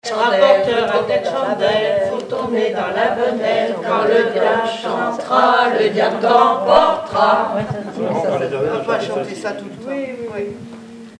formulette enfantine : amusette
Pièce musicale inédite